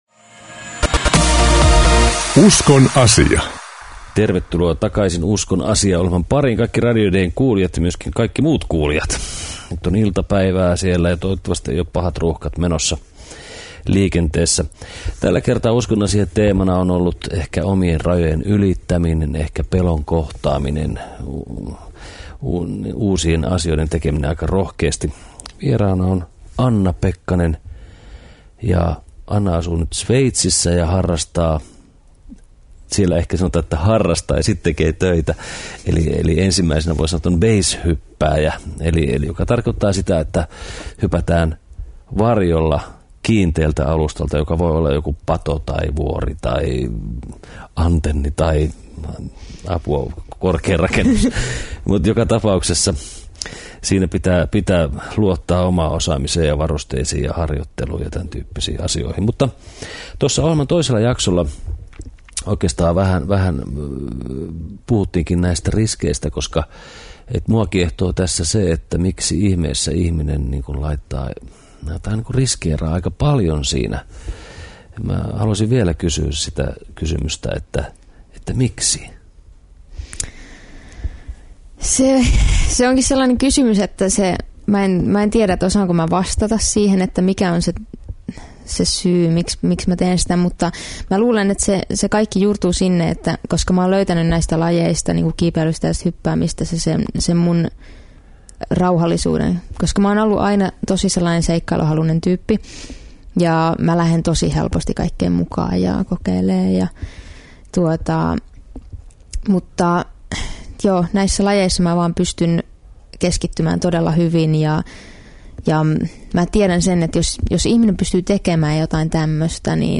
Keskustelua itsensä ylittämisestä ja rajoista.